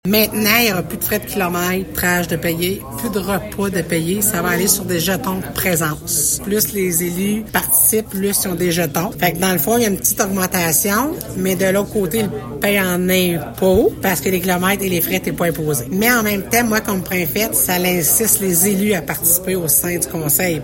La préfète de la Vallée-de-la-Gatineau, Chantal Lamarche, en dit davantage :